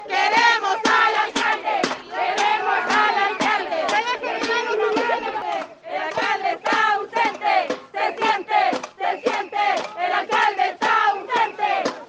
Ese fue parte del ambiente que por más de una hora mantuvieron las mujeres en la calle, donde también se generó congestión.
ambiente-el-peral.mp3